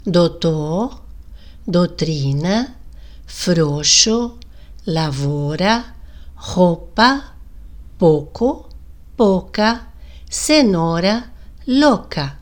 Outra redução de ditongo, acontece com “OU” que reduzimos a “O”